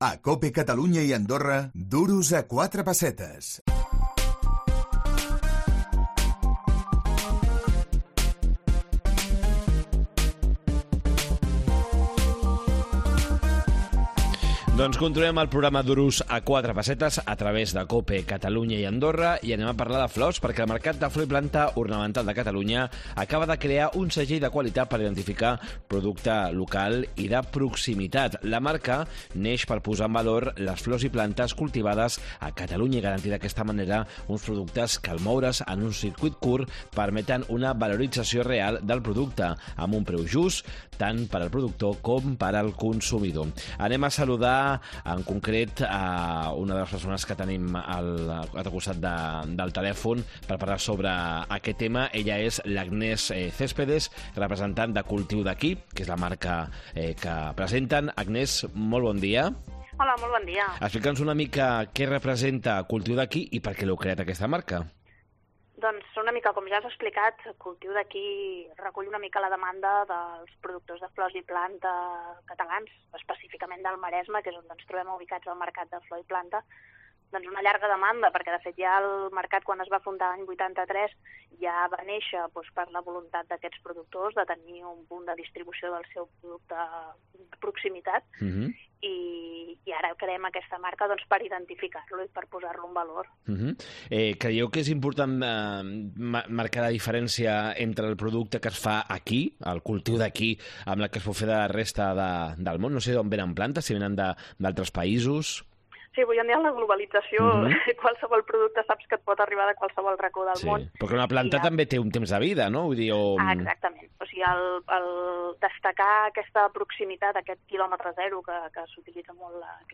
Els productors catalans de flors i plantes creen un segell per identificar el producte local. Entrevista